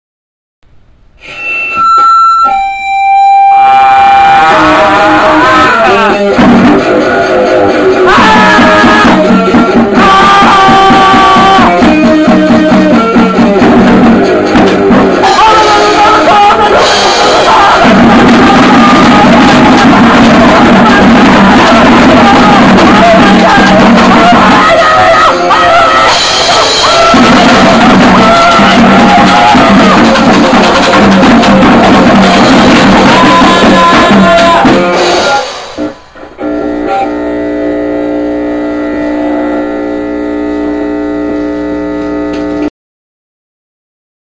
penn state's premiere grindcore band.